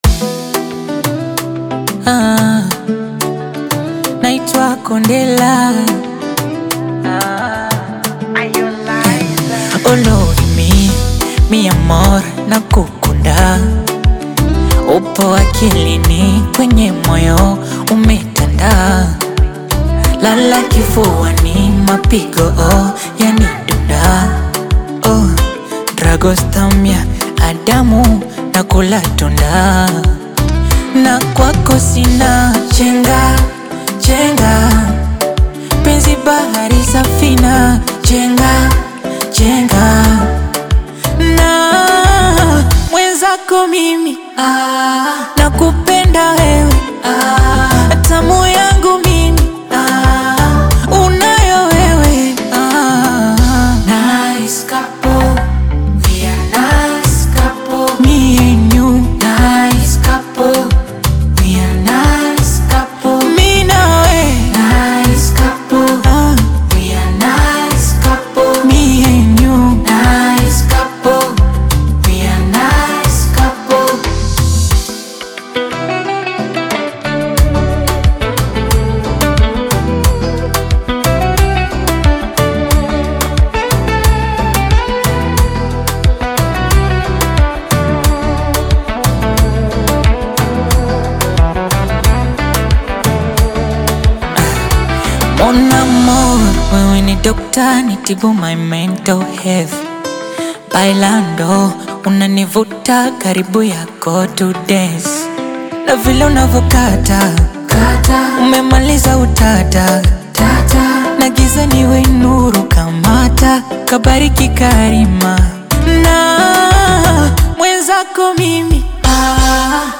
AudioBongo FlavaTanzanian Music